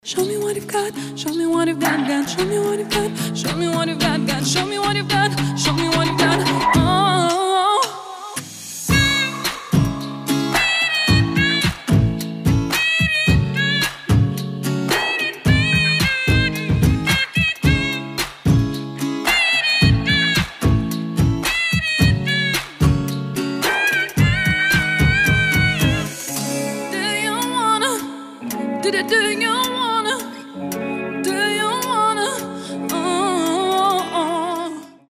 • Качество: 320, Stereo
гитара
чувственные
красивый женский голос
живой звук